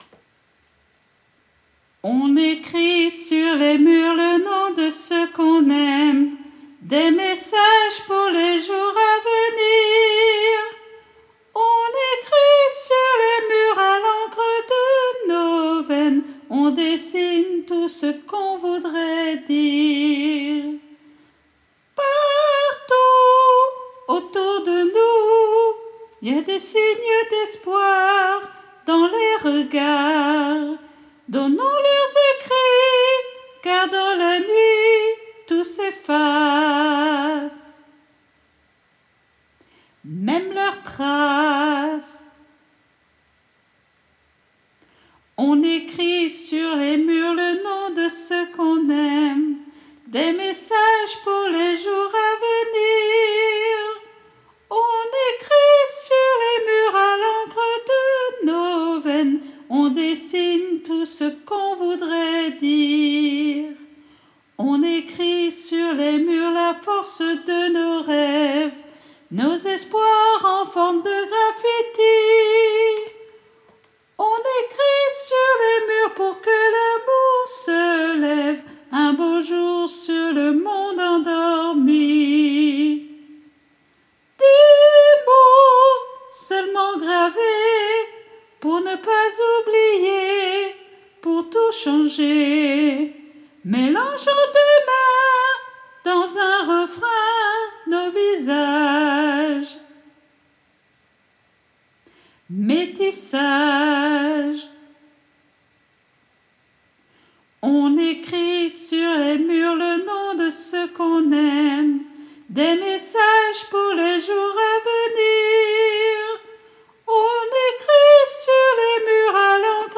MP3 versions chantées